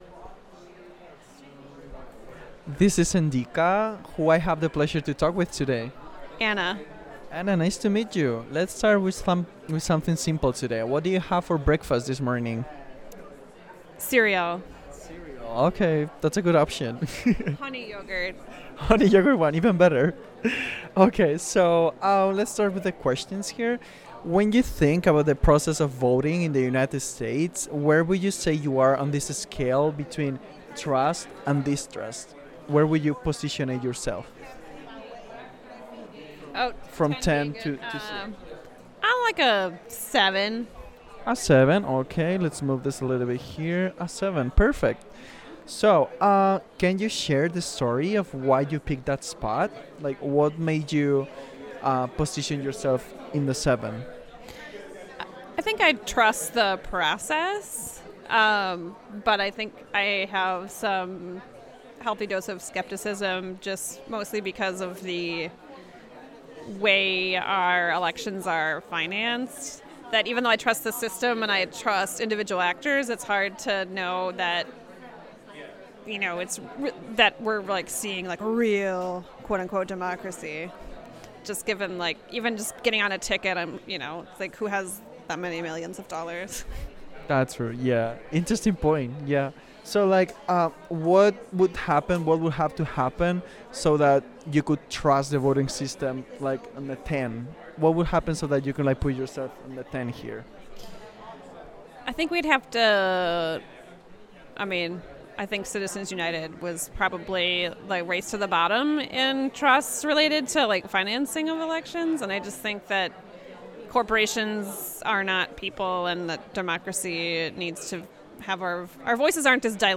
Interview
Location UEC Riverside